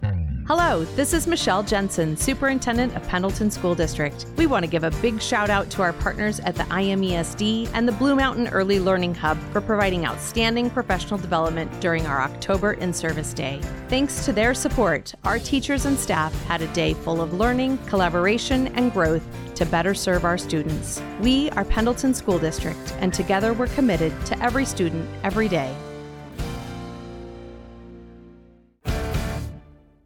Latest Radio Spot